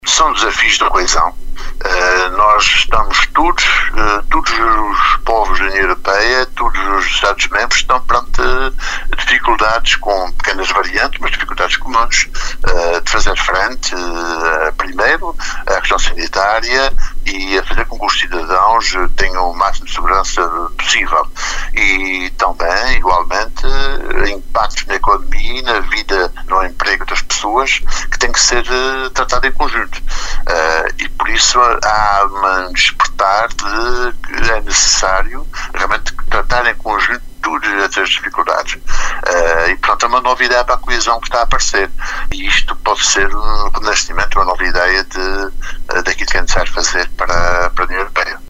A afirmação é do secretário regional Adjunto da Presidência para as Relações Externas, que falava, à Atlântida, em véspera do Dia da Europa.